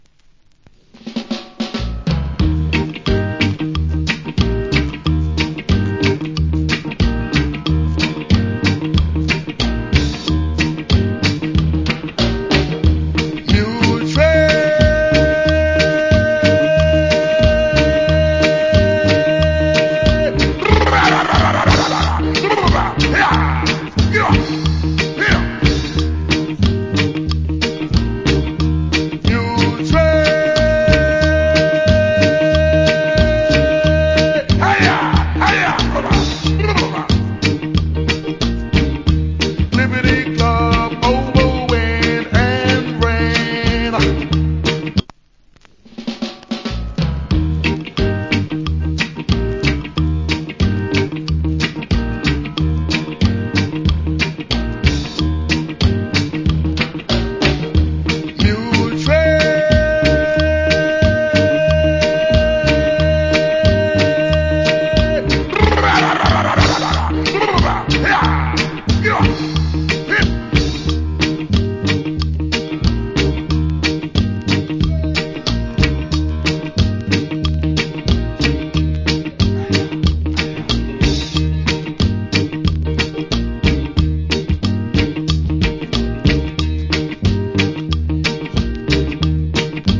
Old Hits Reggae Vocal.